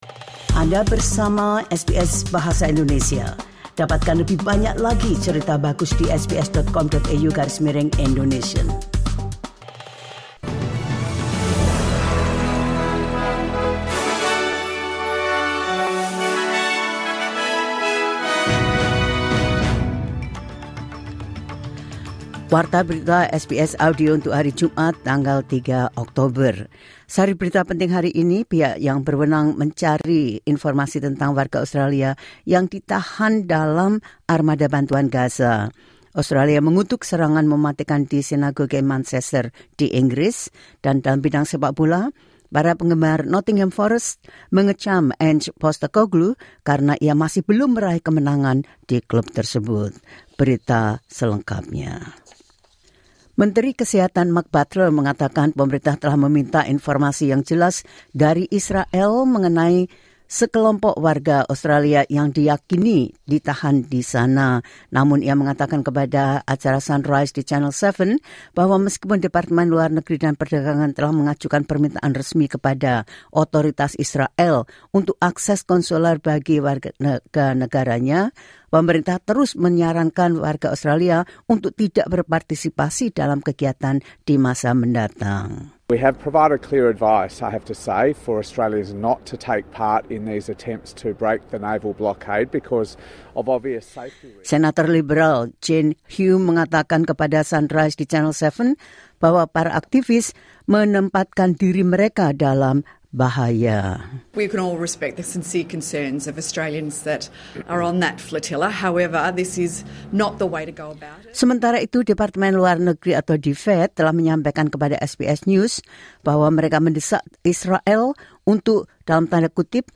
The latest news SBS Audio Indonesian Program – 03 October 2025.